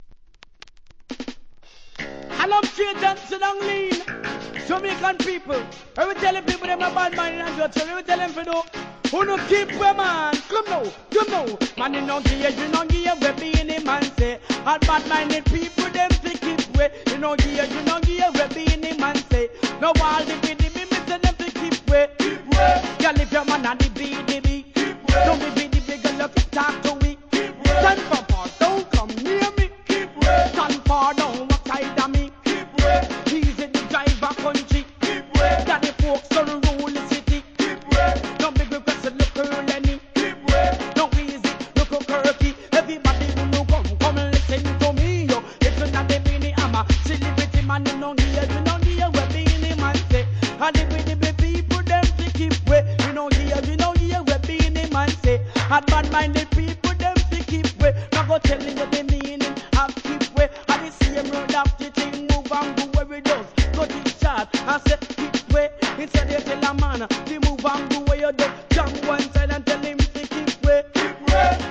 REGGAE
リズミカルな小太鼓のノリがはまるDANCE HALL RHYTHM!!